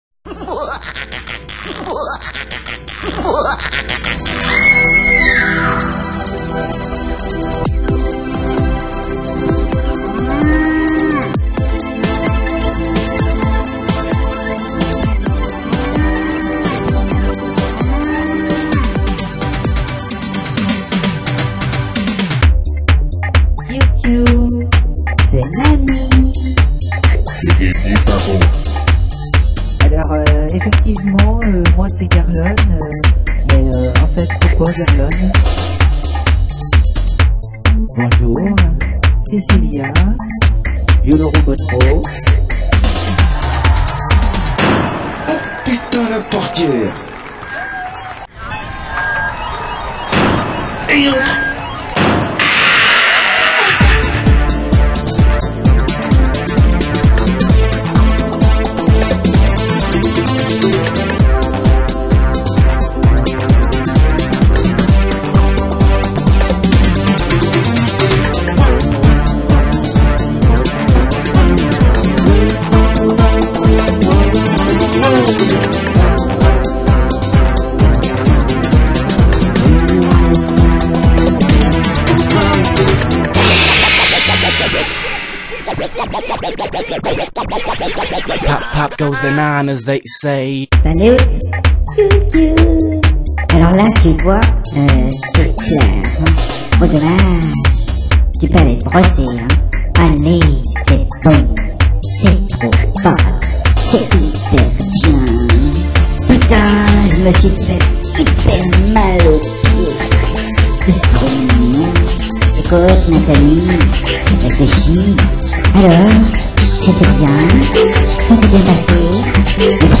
Je prépare souvent mes séquences sur synthés, puis j'enregistre guitares et voix.
La qualité sonore est inférieure à l'original du fait de la compression sévère  ( ! ) mais reste acceptable